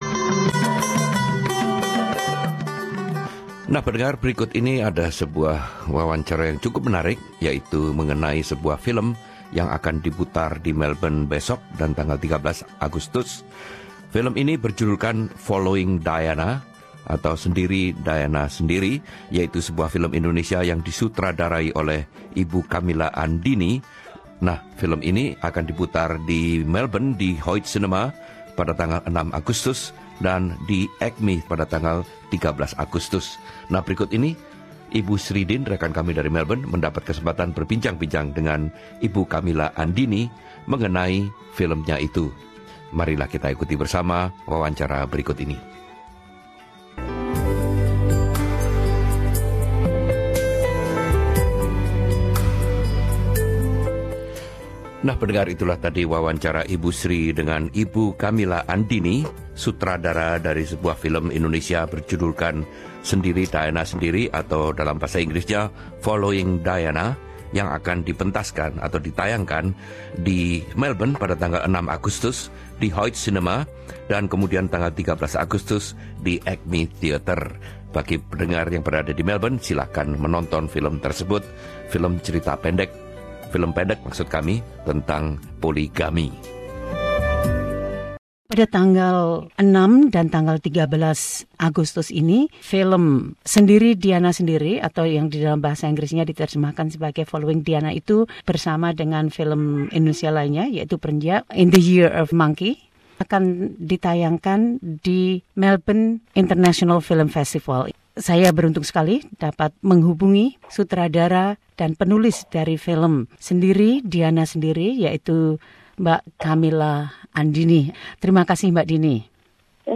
Kamila Andini, sutradara dan penulis film Sendiri Diana Sendiri (Following Diana) berbicara tentang film tersebut, yang akan ditayangkan di Melbourne International Film Festival (MIFF) pada tanggal 6 dan 13 Agustus yang merupakan bagian dari sesi penayangan yang disebut South East Asian Short Films.
Bagaimana film ini berbeda dengan film-film lain tentang dilema poligami? Sutradara dan penulis Kamila Andini menjawab semuanya itu dan pertanyaan terkait lainnya.